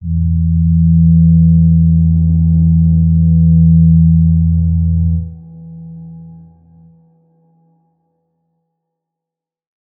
G_Crystal-E3-mf.wav